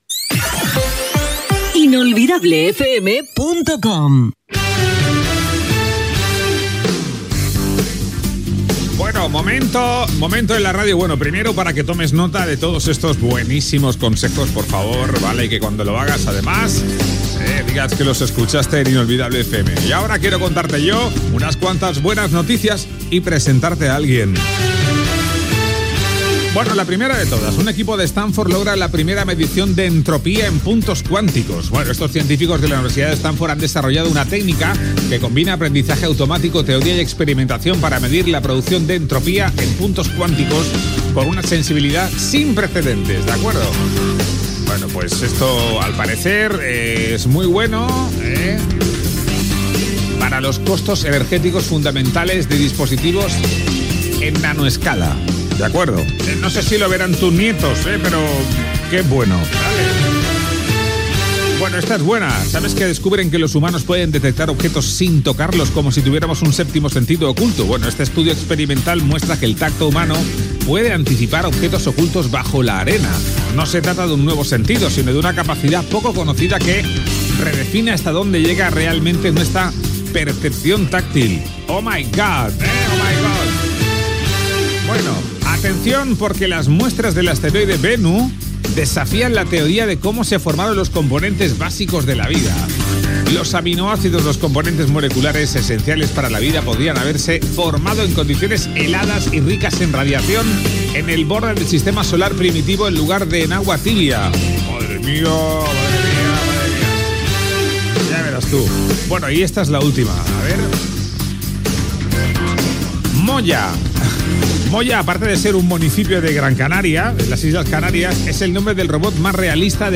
Indicatiu, informacions tecnològiques i científiques, sobre la publicitat radiofònica. Avi la veu sintètica que dóna la informació del temps. Tema musical
Avi (Veu sintètica)